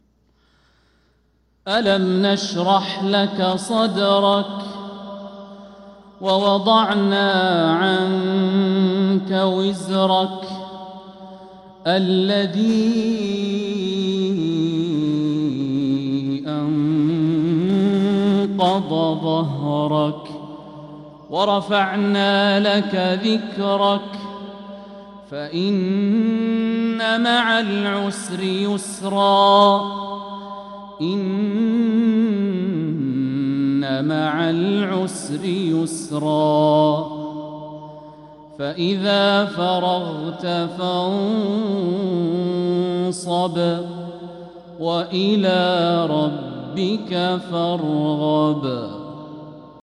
من الحرم المكي 🕋